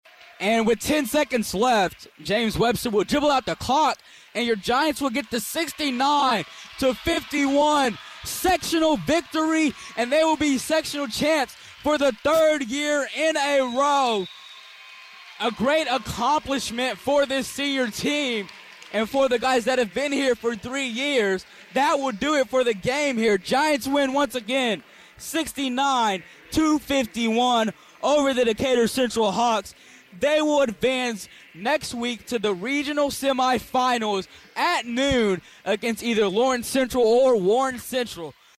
calls the final seconds of BD's 69-51 win over Decatur Central to claim a third-straight Sectional 11 Championship!